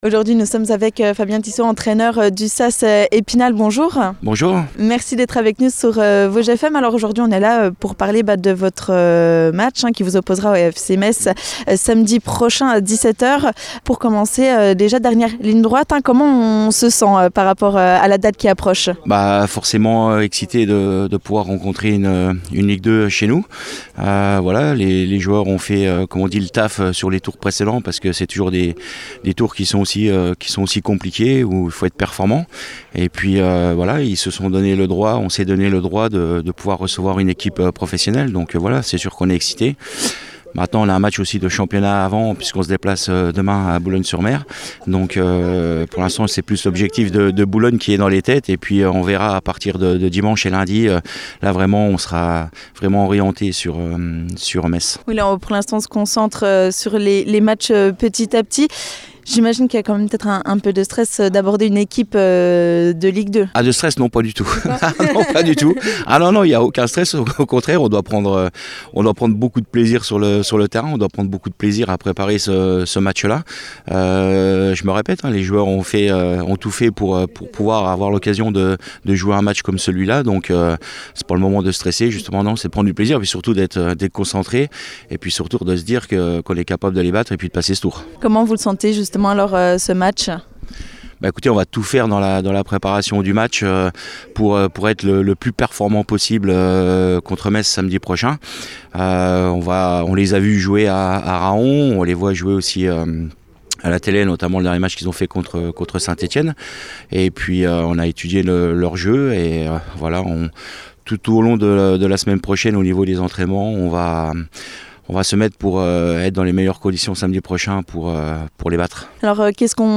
Jeudi dernier, à l'occasion d'un de leurs entraînements, nous sommes allés à la rencontre des joueurs du SAS Epinal.